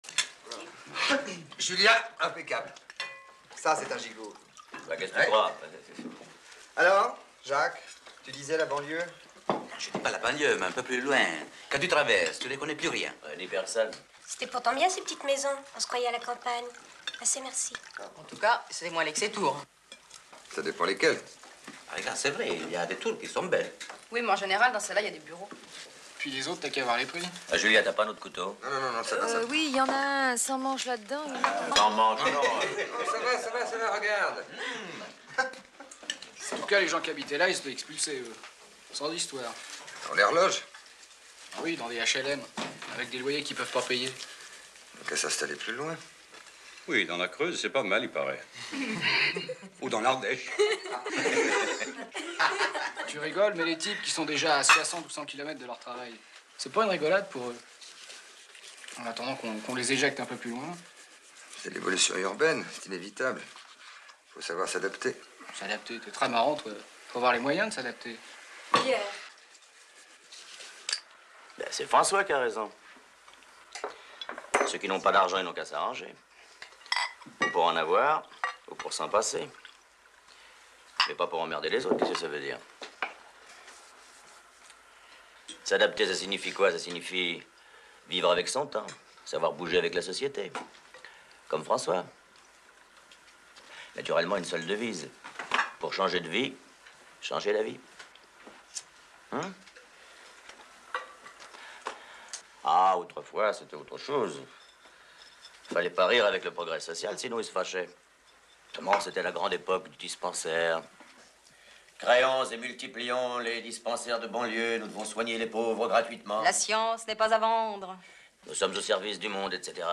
Et le monsieur, à la fin, comment qu'il est colère !!!